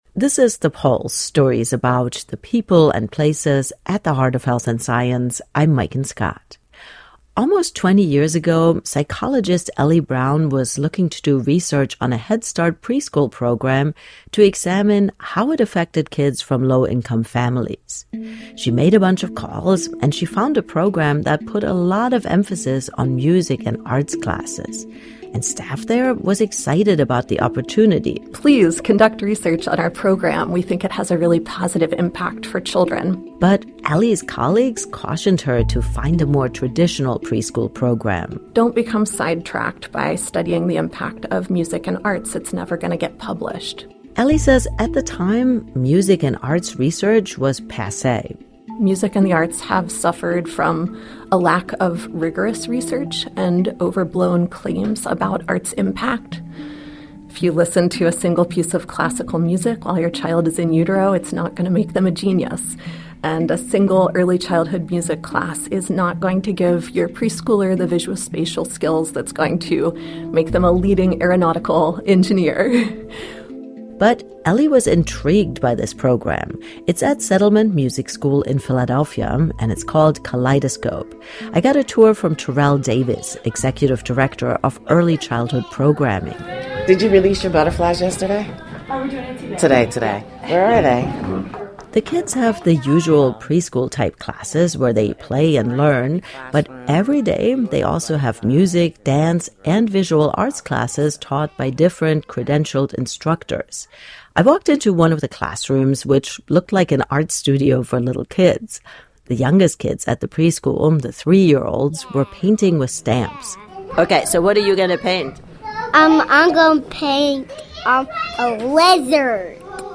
Each week he discusses the latest news in astronomy